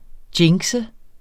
Udtale [ ˈdjeŋgsə ]